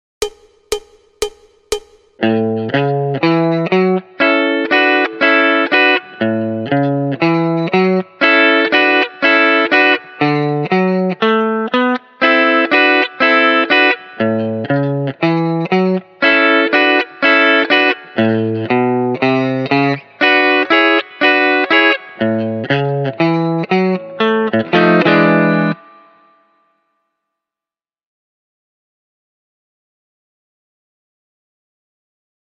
Tablatures pour Guitare